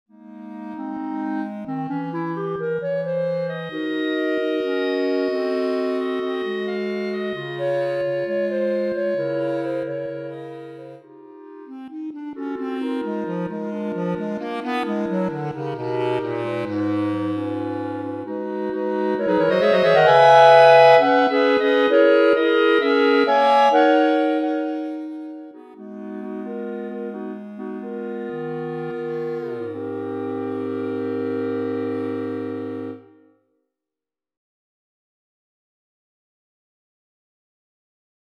Clarinet Quartet Edition
3 Bb clarinets + Bb bass clarinet.